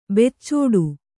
♪ biccaḍi